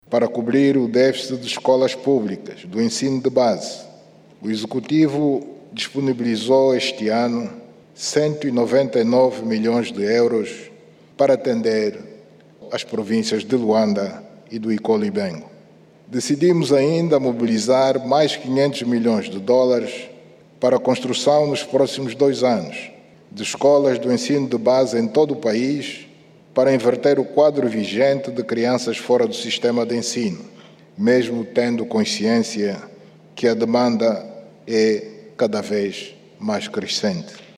O anúncio foi feito em Luanda, pelo Presidente da República, no discurso de abertura da Conferência Nacional sobre o Capital Humano, que encerrou, em Luanda.